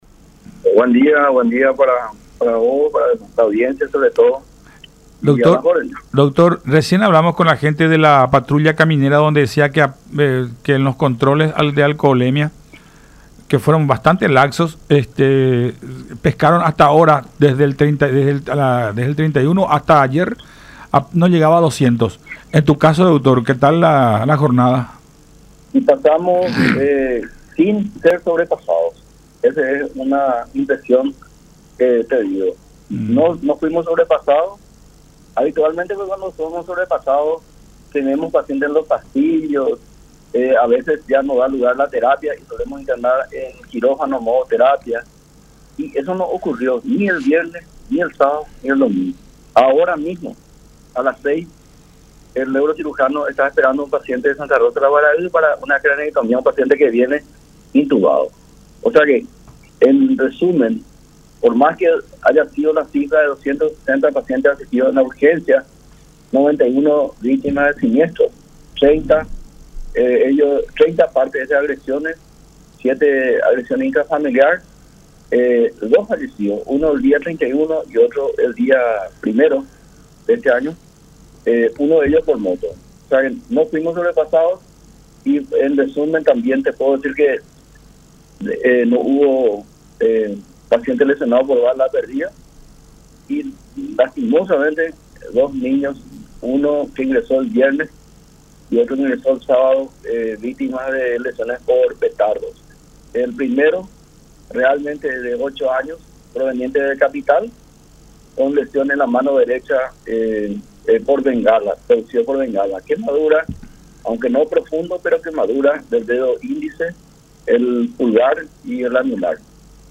en diálogo con Enfoque 800 a través de La Unión